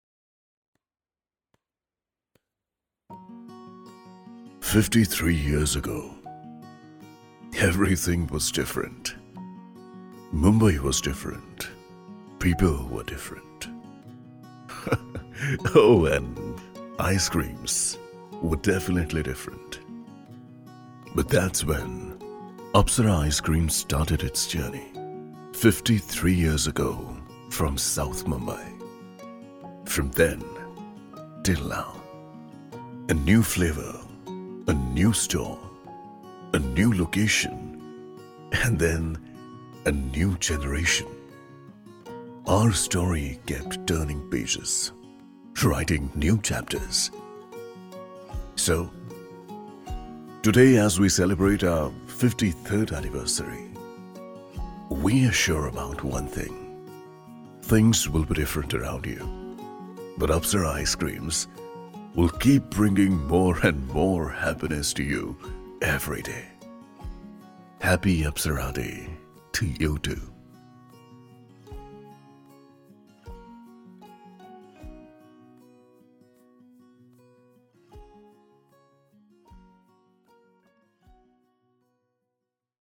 Whenever you need a deep, baritone, sonorous voice to show your product to the world, you can get all of it from my voice.
English Narration (Genre - Middle Aged, Nostalgic ).mp3